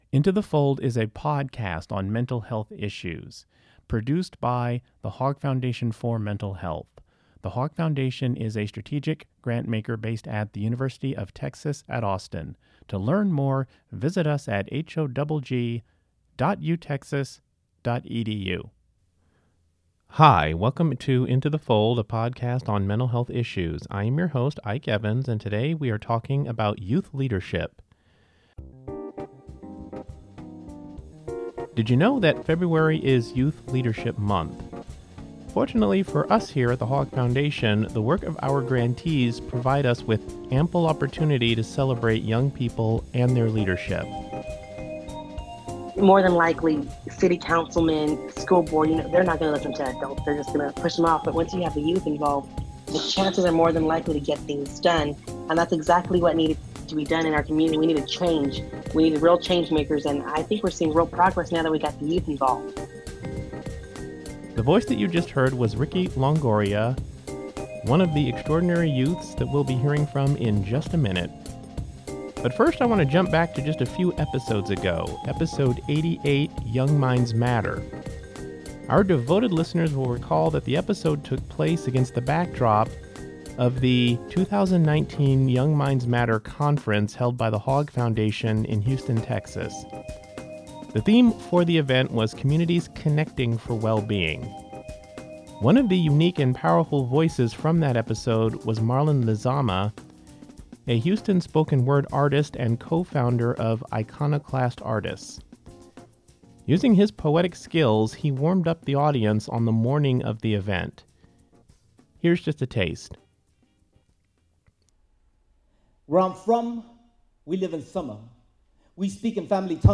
February is Youth Leadership Month. In this episode of Into the Fold, we talk with three young people who are change-makers in their communities.